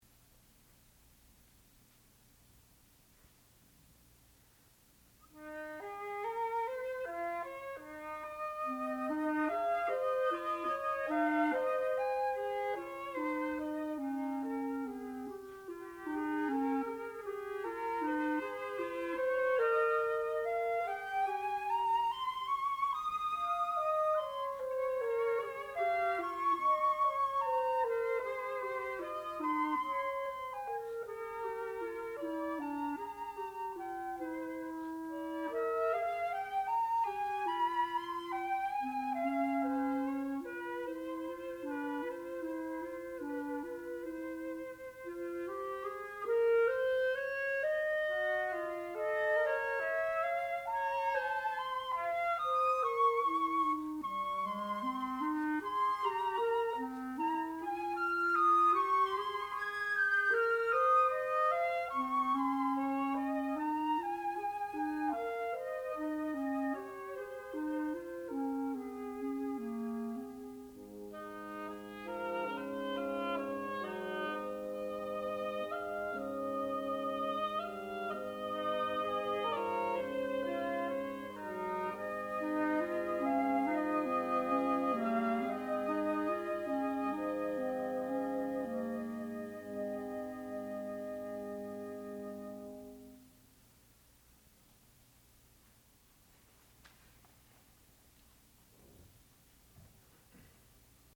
classical music
oboe
clarinet
flute